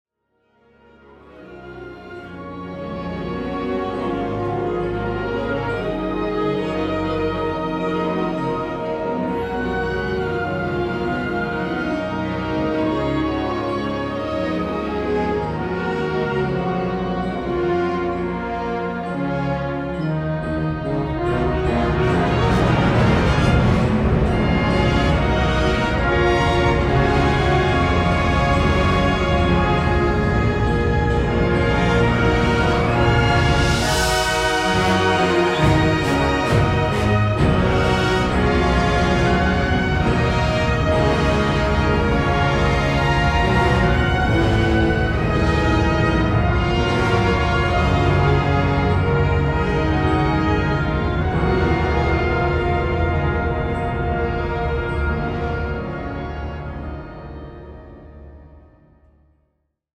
Schweizer Erstaufführung